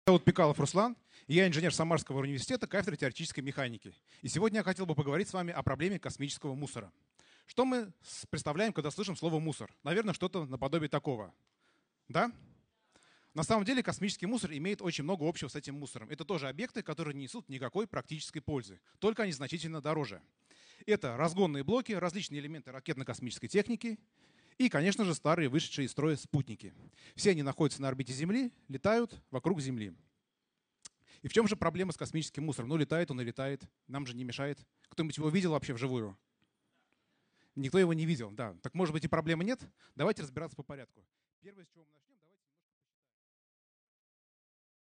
Аудиокнига Субботник на орбите | Библиотека аудиокниг
Прослушать и бесплатно скачать фрагмент аудиокниги